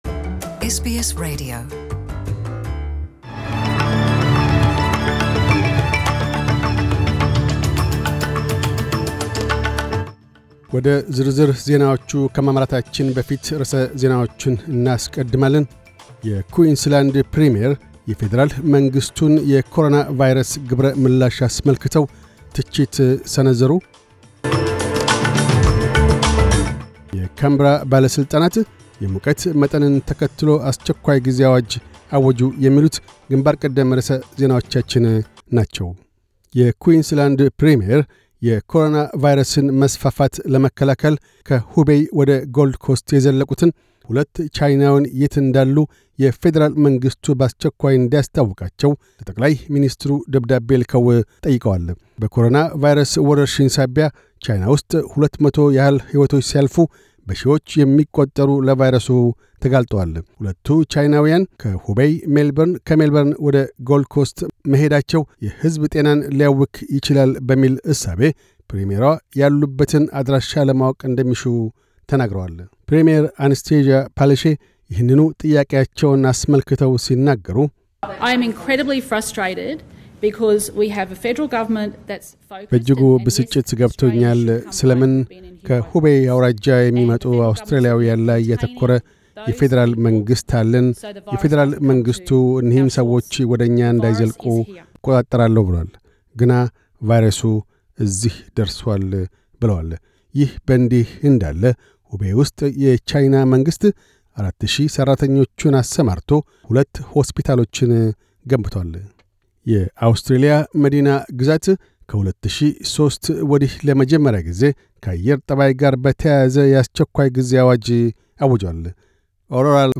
News Bulletin 3101